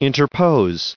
added pronounciation and merriam webster audio
1623_interpose.ogg